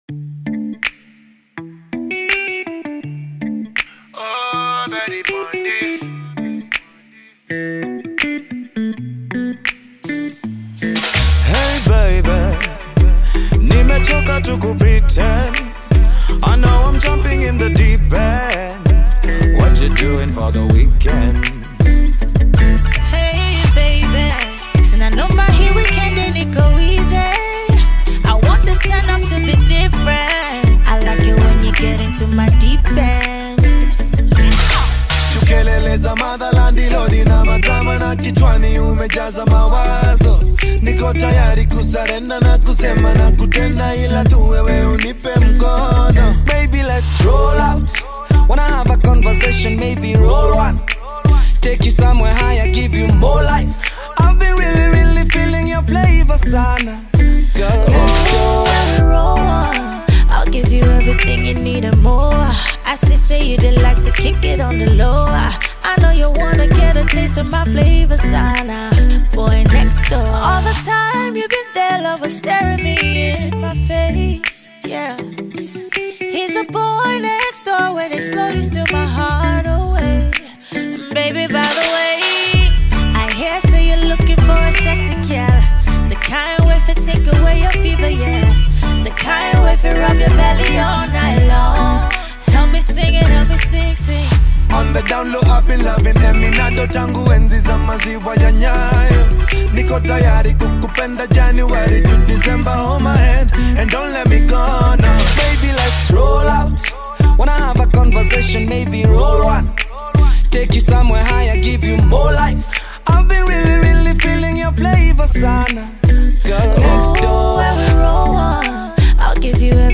The beautiful tune with catchy vibe